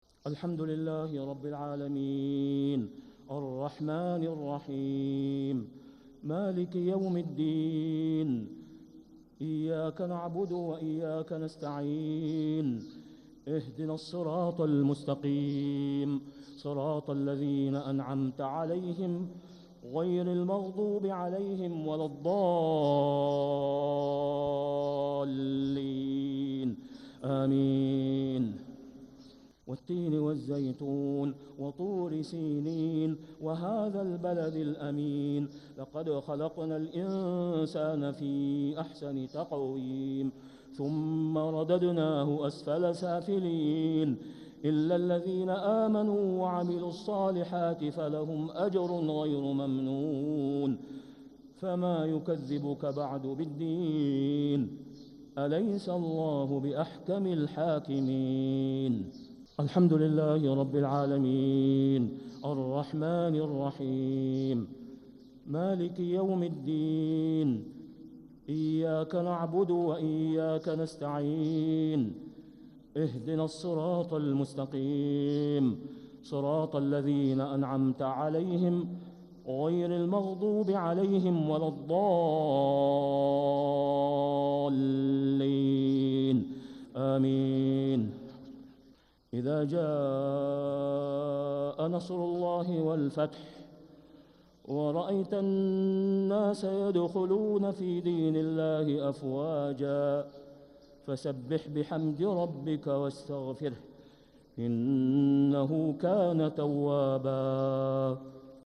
صلاة الجمعة 14 صفر 1447هـ سورتي التين و النصر كاملة | Jumu’ah prayer Surah At-Tain and An-Nasr 8-8-2025 > 1447 🕋 > الفروض - تلاوات الحرمين